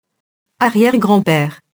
arrière-grand-père [arjɛrgrɑ̃pɛr] nom masculin (pluriel arrière-grands-pères)